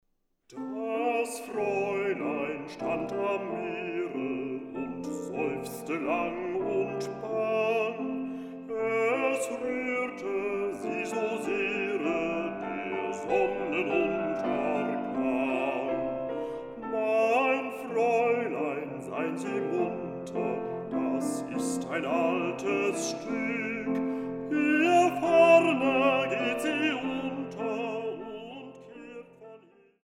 Bassbariton
Klavier